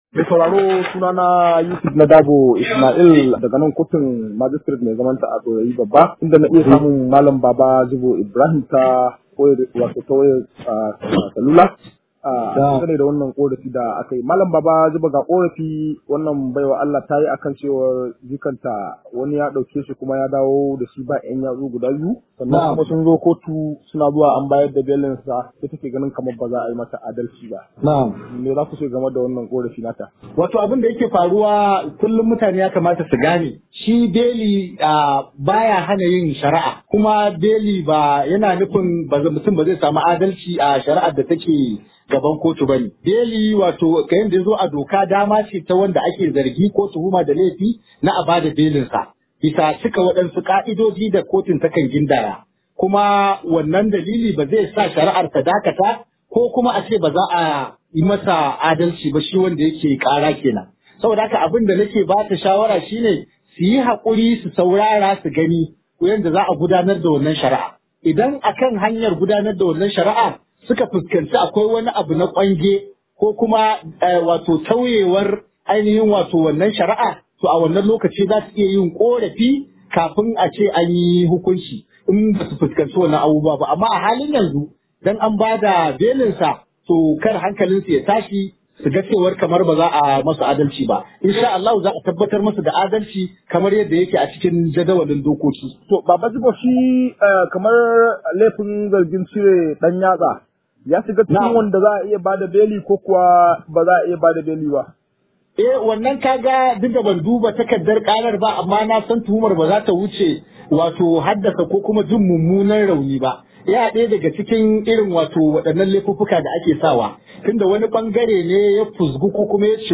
Rahoto
Akwai cikakken rahoton a muryar da ke kasa.